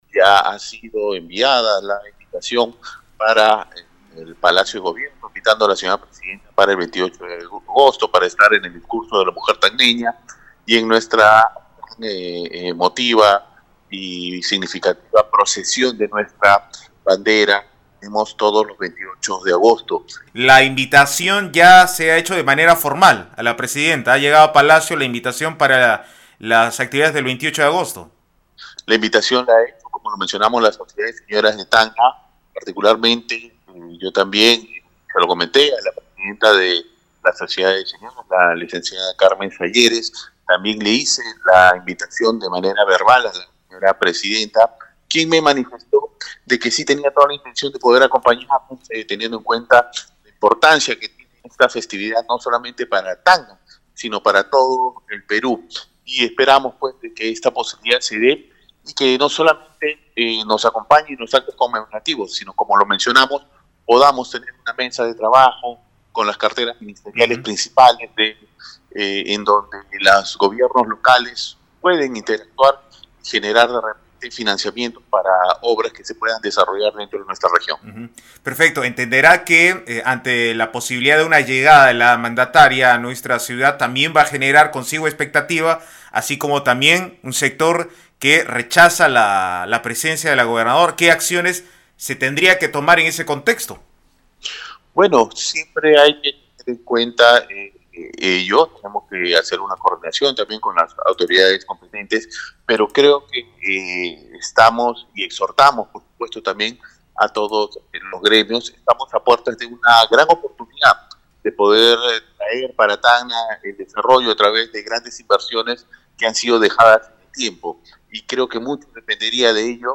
El prefecto regional de Tacna, Stephen Ugarte confirmó a Radio Uno que la invitación formal por los 94 años de reincorporación a la heredad nacional ya fue enviada a Palacio de Gobierno y si bien se encuentra pendiente de respuesta escrita, aseguró que la presidenta Dina Boluarte le expresó de manera verbal su intención de participar de las actividades del 28 de agosto.